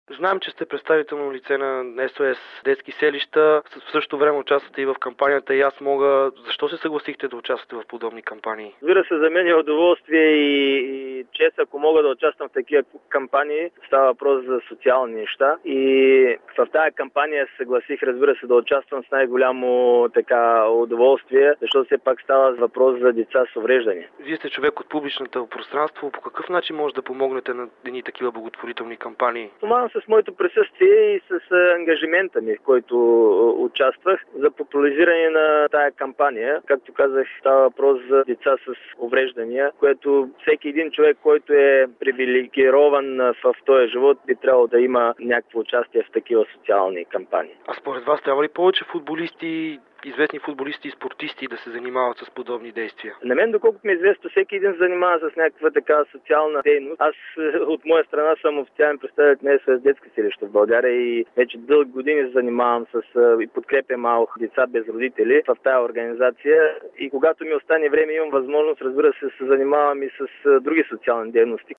Файл 3 – интервю с Красимир Балъков